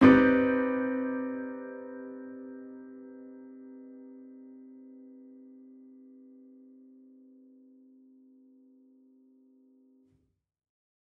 Index of /musicradar/gangster-sting-samples/Chord Hits/Piano
GS_PiChrd-Cmin9maj7.wav